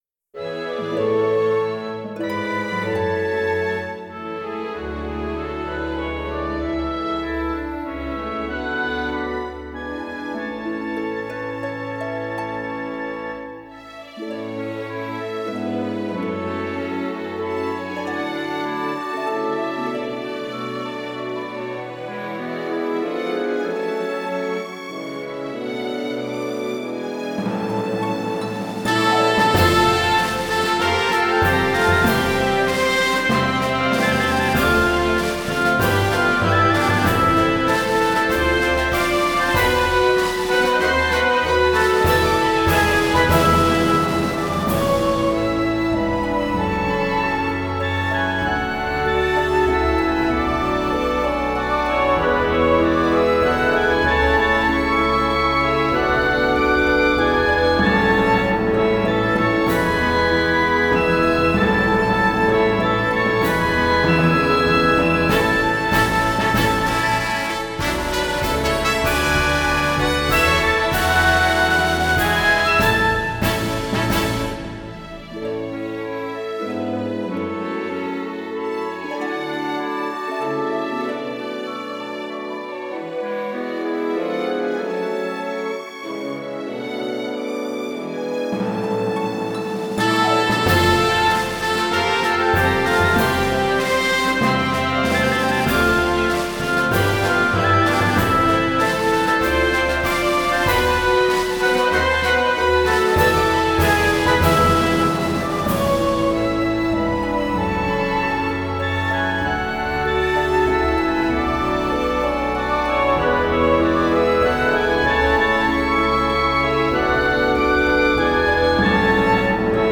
イ長調）管弦楽インスト